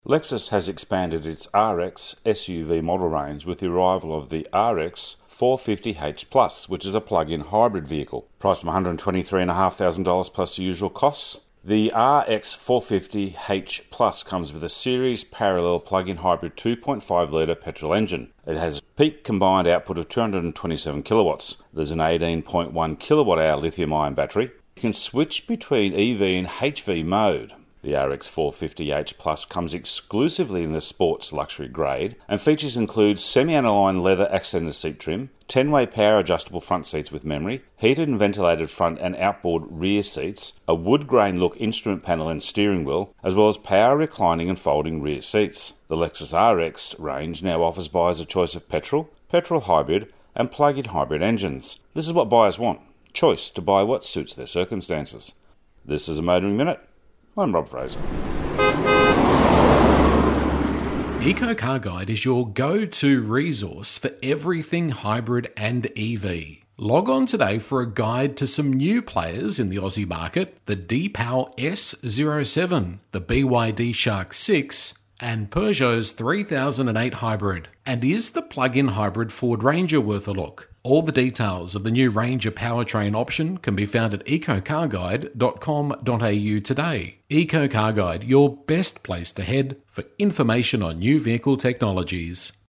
Radio Segments Motoring Minute